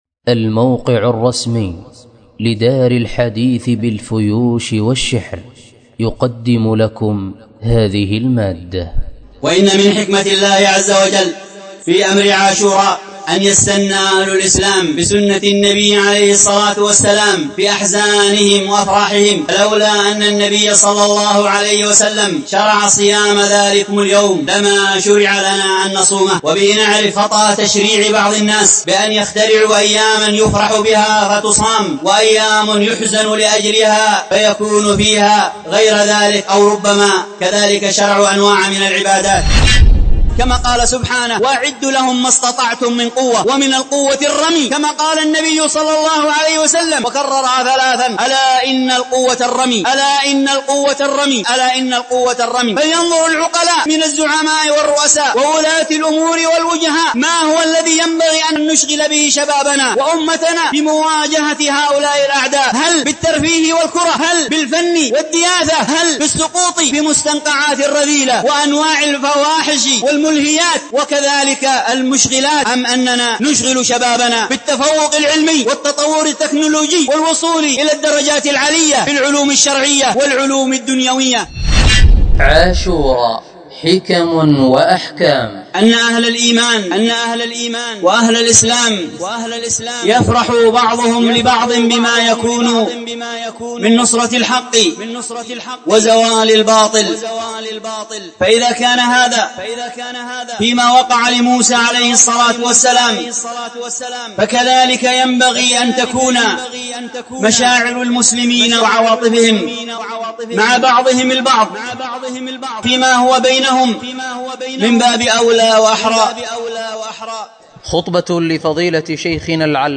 الدرس في شرح الباعث الحثيث 33